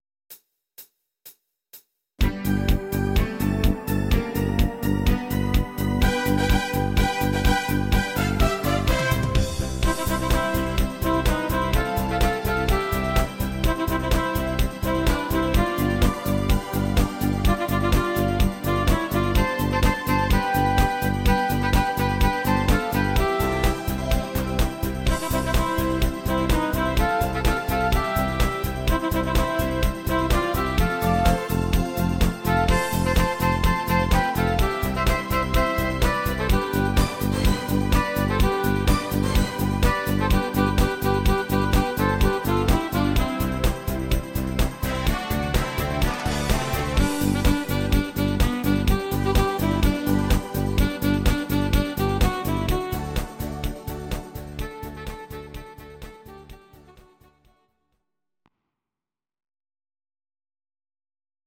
These are MP3 versions of our MIDI file catalogue.
Please note: no vocals and no karaoke included.
Your-Mix: Duets (554)